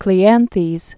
(klē-ănthēz) 331?-232?